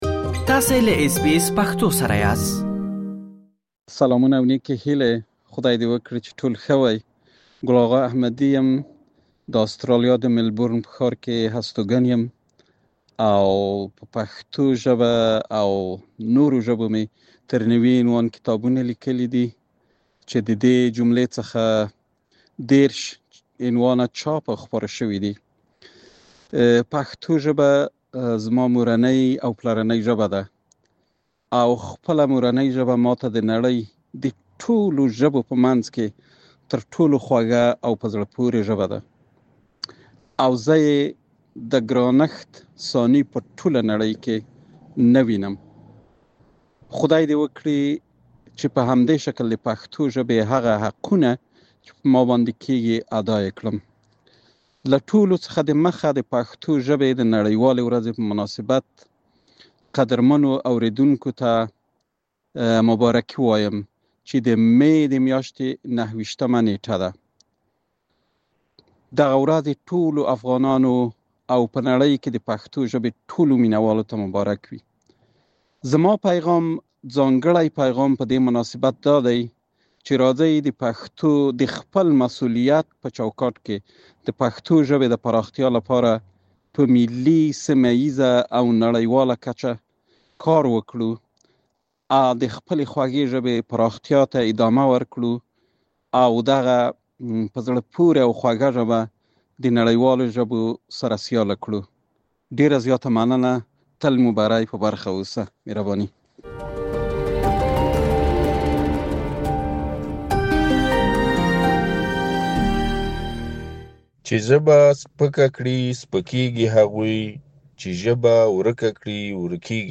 د غبرګولي اتمه یا د مې ۲۹ مه د پښتو ژبې ورځ ده. په آسټرالیا کې مېشتو یو شمېر افغانانو د دغې ورځې په مناسبت له اس بي اس پښتو سره خپل نظرونه شریک کړي.